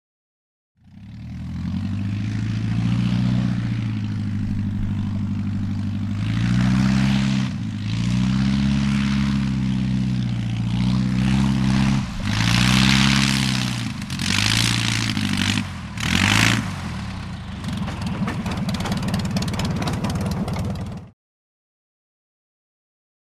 Prop Plane; Idle / Taxi; Fokker Single Engine Prop Aircraft Circa 1914 Rev And Motor Idling In Long Shot, Then Approaches With Revs, Stops At Mic And Motor Idling.